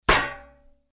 Звуки ударов предметов
Сковородкой по башке